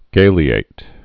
(gālē-āt) also ga·le·at·ed (-ātĭd)